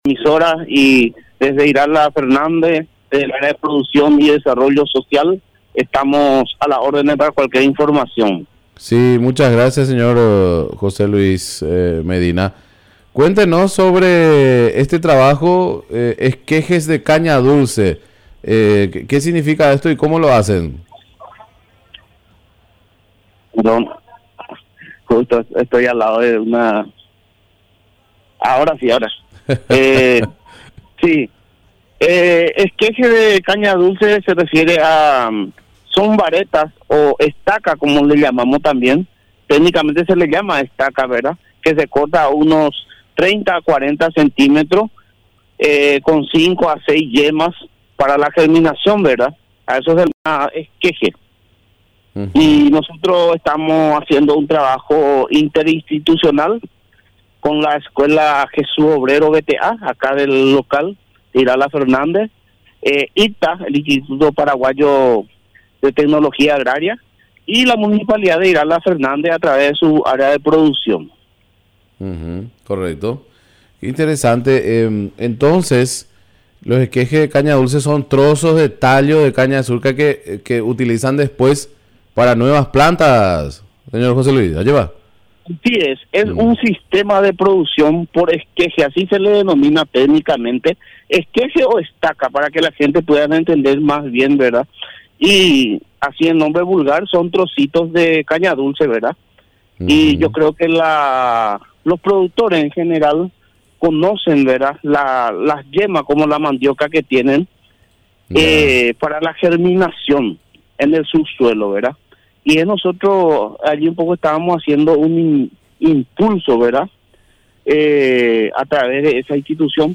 Entrevistas / Matinal 610 Producción de esquejes de caña dulce Jul 08 2025 | 00:14:13 1x 00:00 / 00:14:13 Subscribe Share RSS Feed Share Link Embed